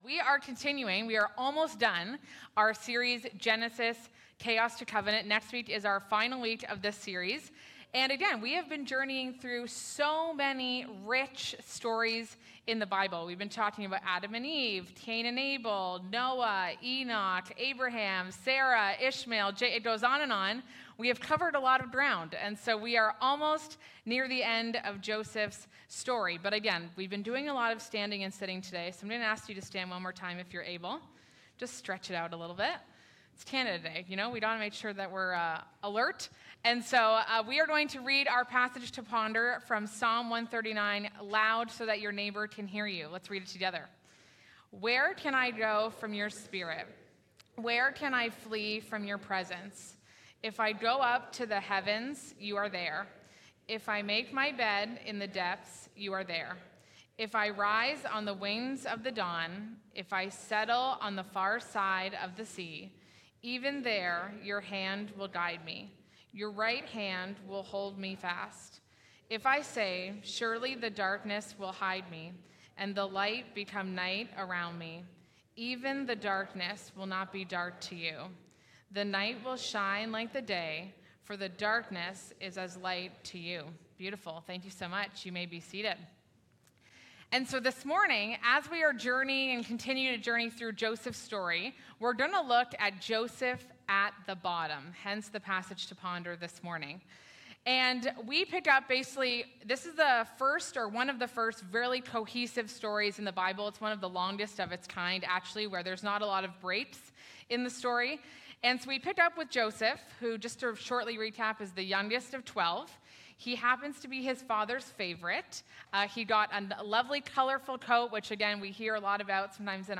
Listen to our scripture talks from our Sunday morning gatherings.